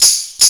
TAMB LOOP2-R.wav